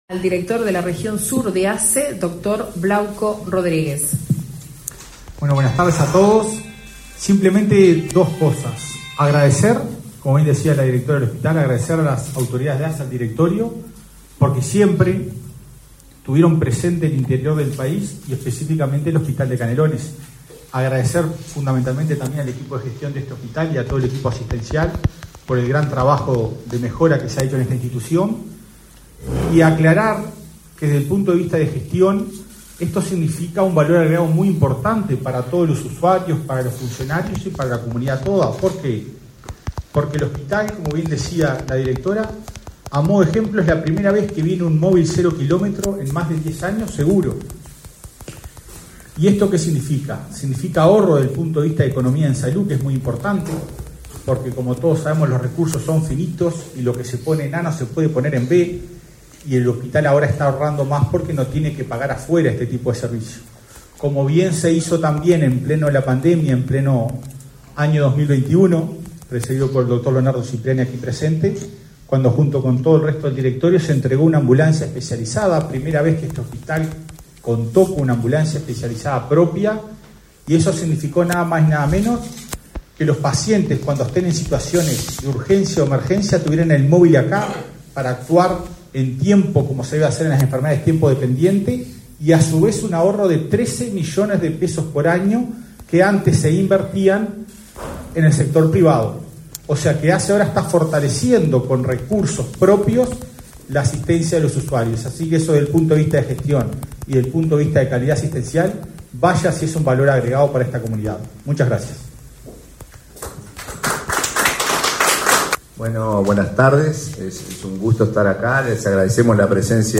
Acto por la entrega de vehículo de traslado para el hospital de Canelones
En la ceremonia participó el presidente de ASSE, Marcelo Sosa, y el director de la Región Sur de ASSE, Blauco Rodríguez.